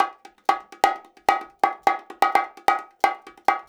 130BONGO 03.wav